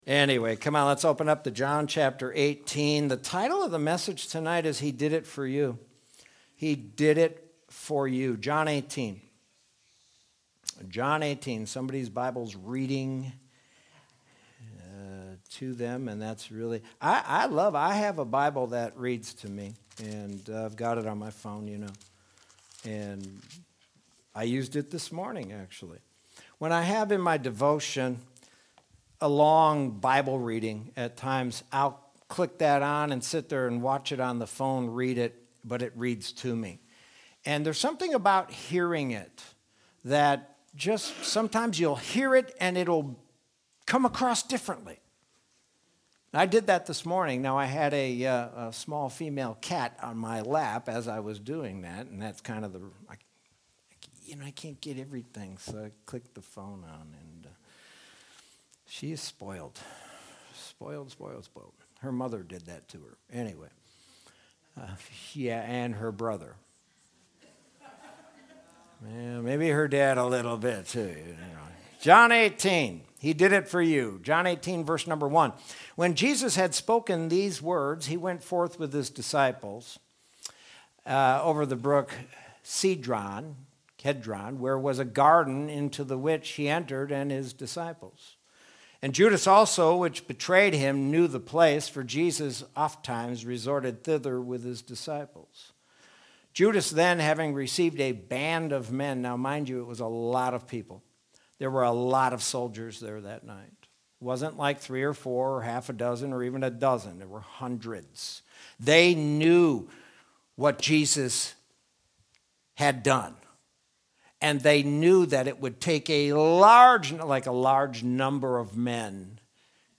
Sermon from Wednesday, March 31st, 2021.